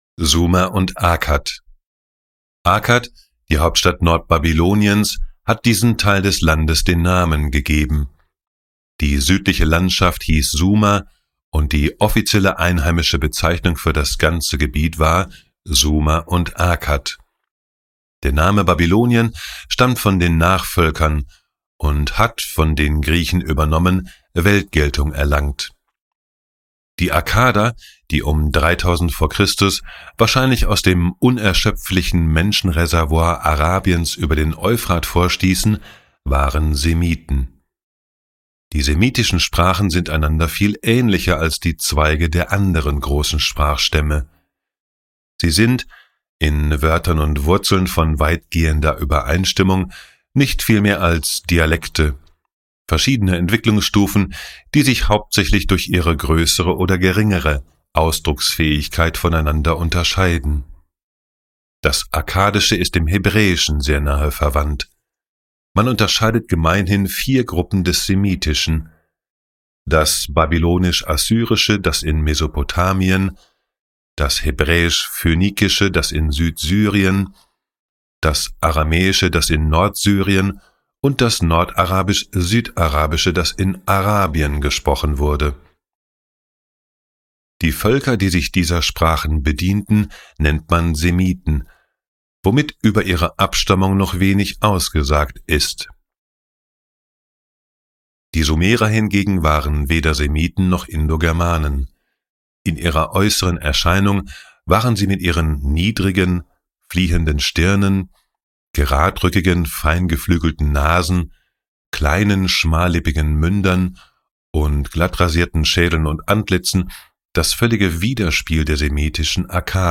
Nordmänner-Saga - Wikinger-Träume für moderne Krieger (Hörbuch)